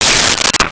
Space Gun 01
Space Gun 01.wav